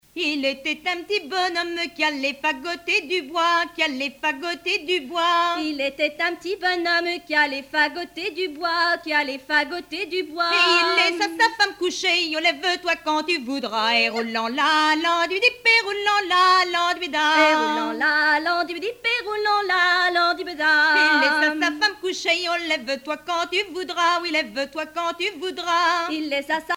danse : riqueniée
Pièce musicale éditée